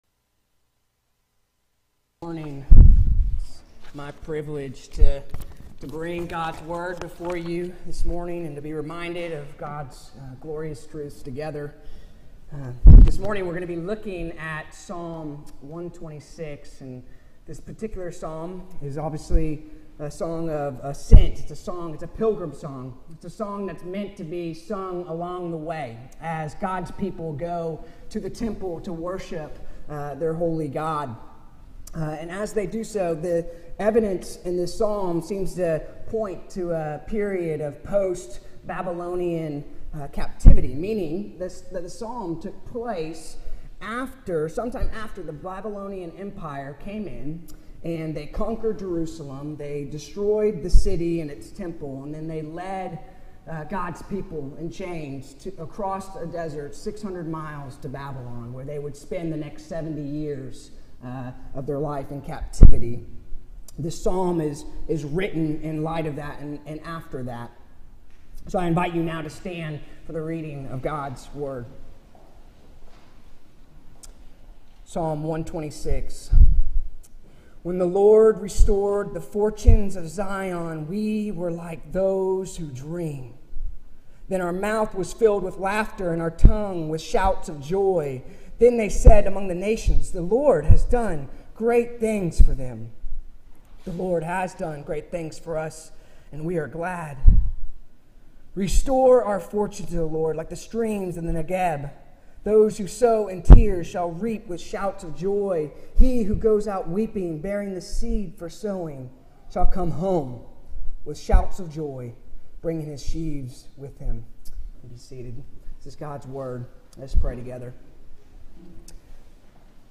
Sermons on the Psalms